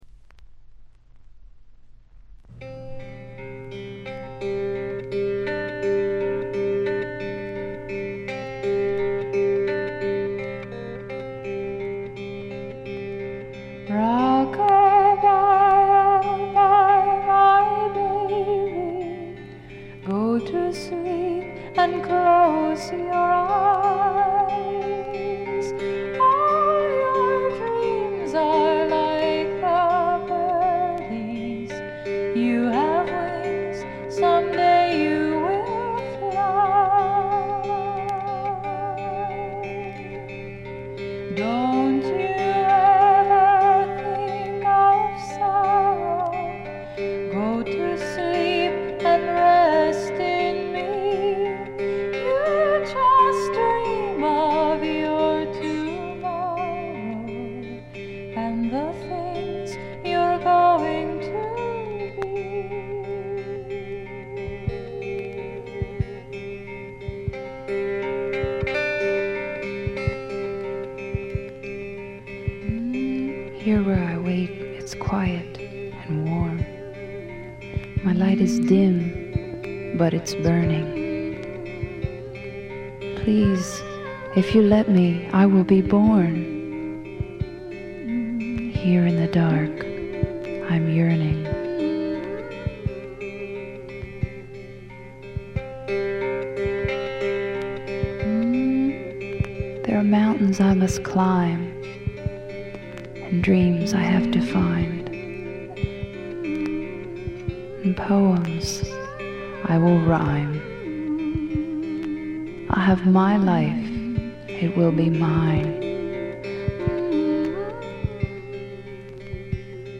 ほぼ全曲自作もしくは共作で、ギター弾き語りが基本の極めてシンプルな作りです。
試聴曲は現品からの取り込み音源です。